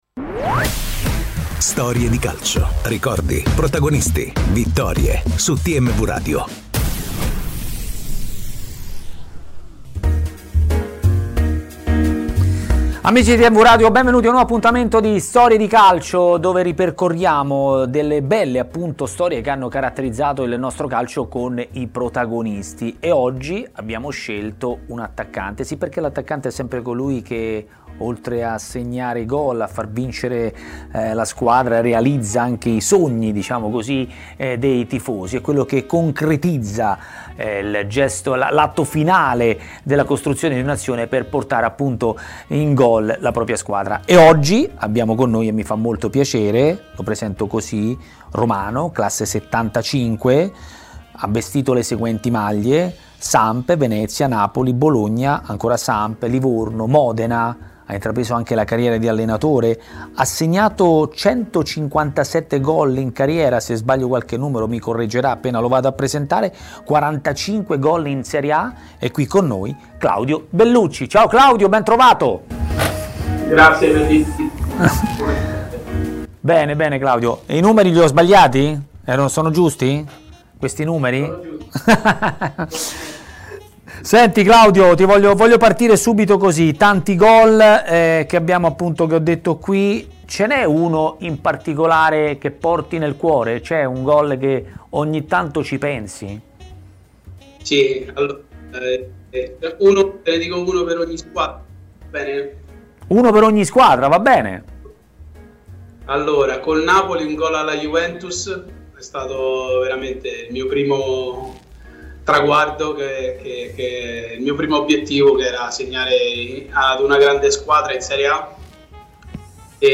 E di momenti del genere ne ha vissuti anche un bomber come Claudio Bellucci, che si è raccontato in Storie di Calcio, trasmissione di TMW Radio.